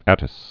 (ătĭs)